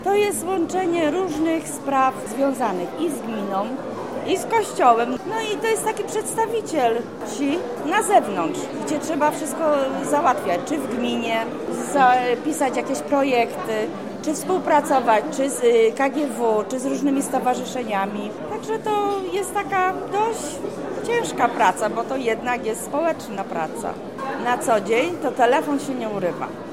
W Teatrze im. Stefana Jaracza w Łodzi odbył się Wojewódzki Dzień Sołtysa.